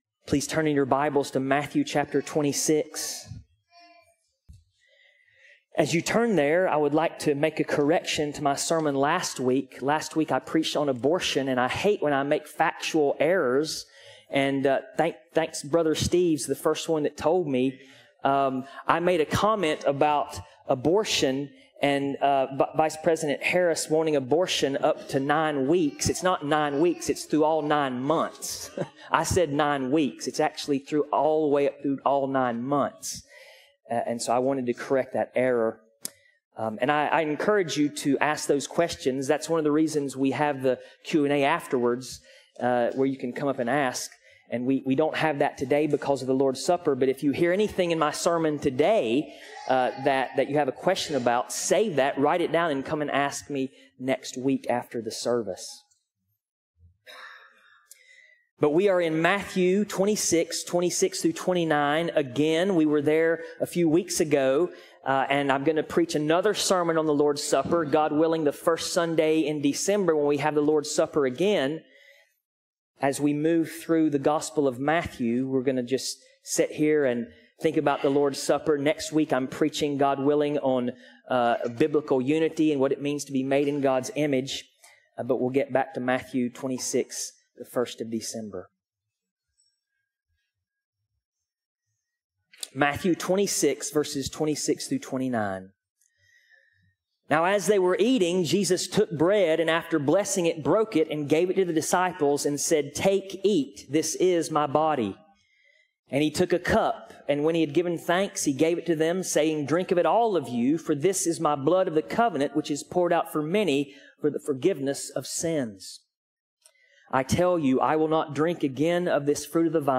Passage: Matthew 26:26-29 Part 2 Service Type: Sunday Morning